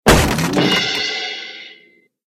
wood_joint_break_04.ogg